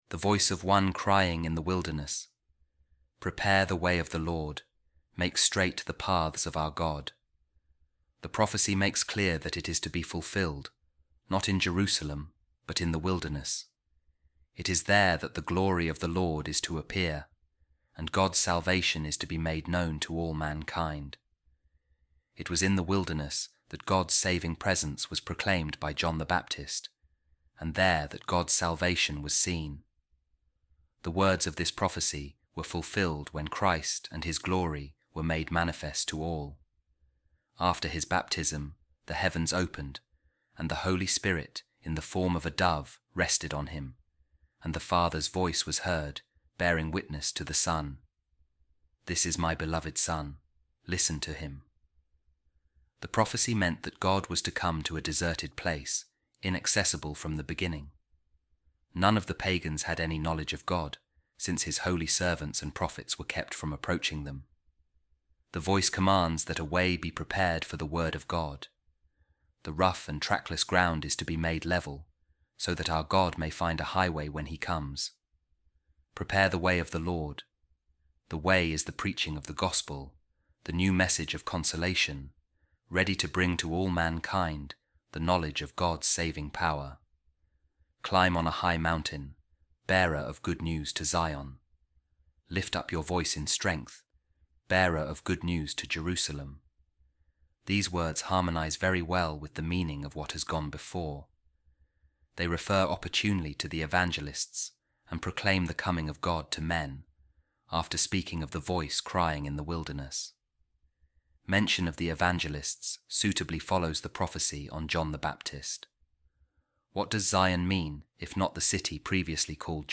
A Reading From The Commentary On Isaiah By Eusebius Of Caesarea | A Voice Of One Crying In The Wilderness